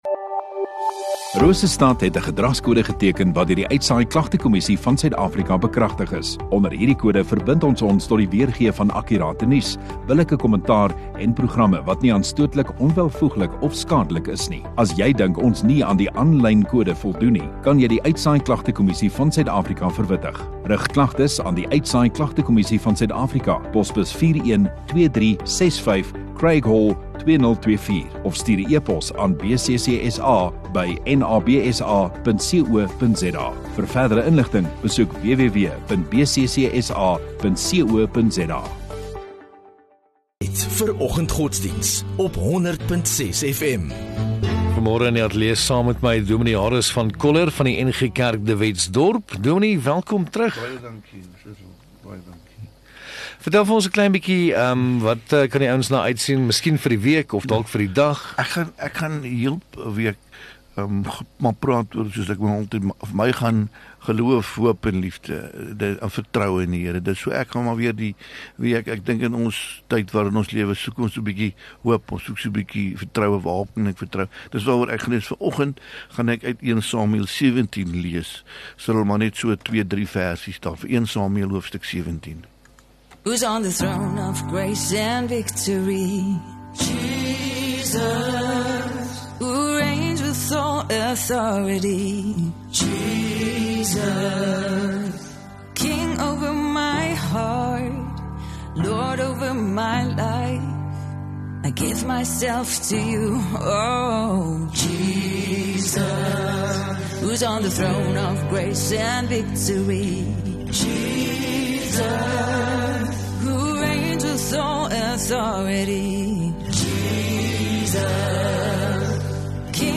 2 Oct Maandag Oggenddiens